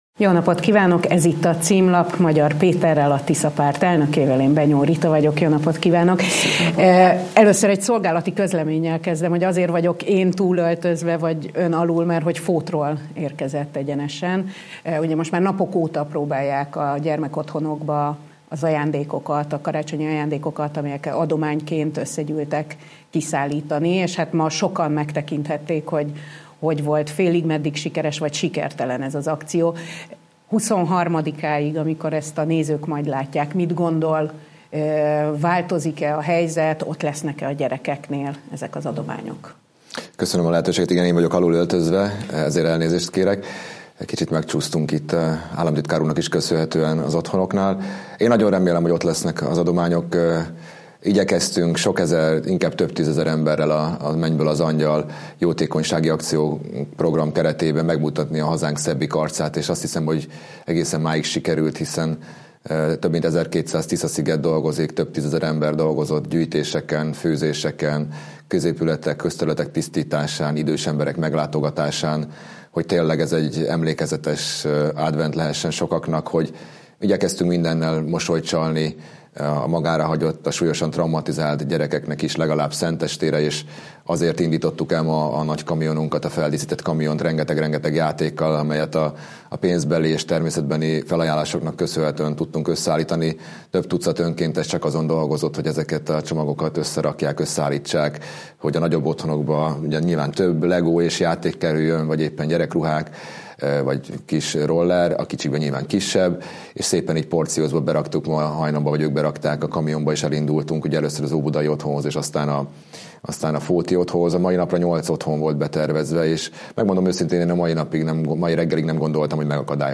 A Tisza Párt elnöke először beszél arról, milyen azonnali szakpolitikai lépéseket tenne kormányváltás esetén. Év végi nagyinterjú Magyar Péterrel.